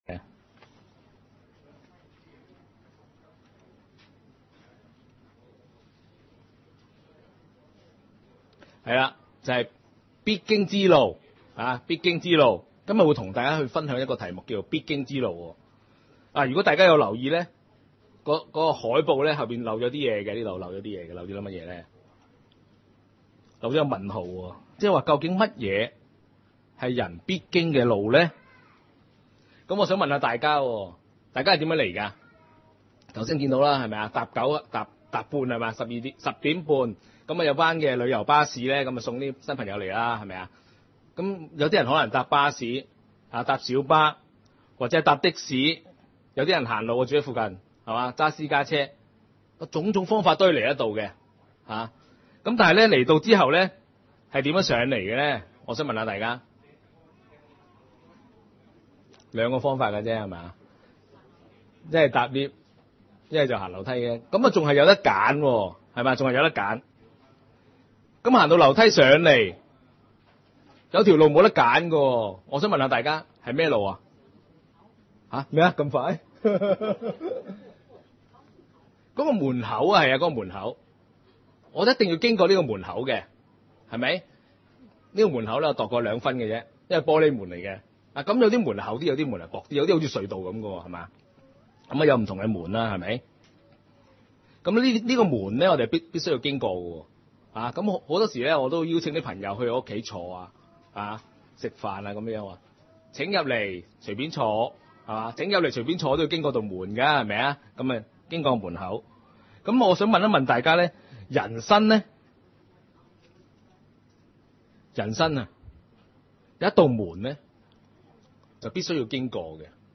福音聚會： 必經之路？